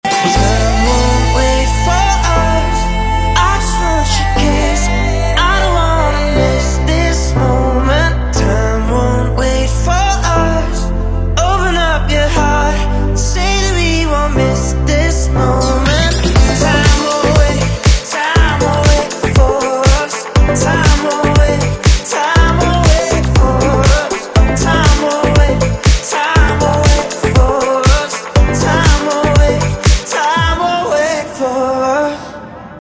танцевальные
качающие , битовые , диско